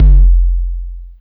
53 BD 1   -R.wav